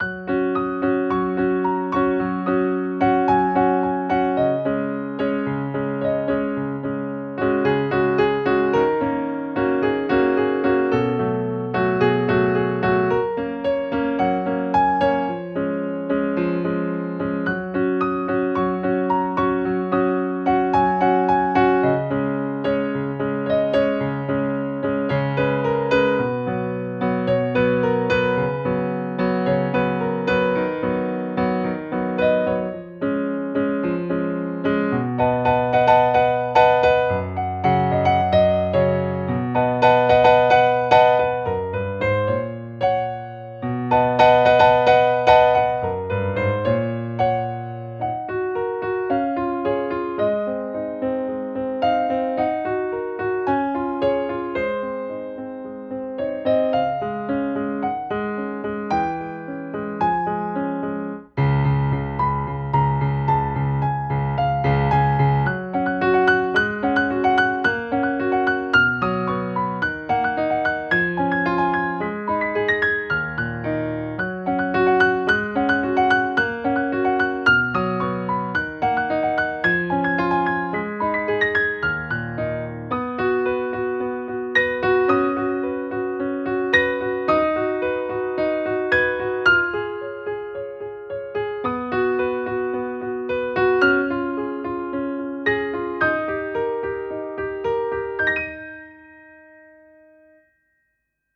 фортепианная классическая музыка такого плана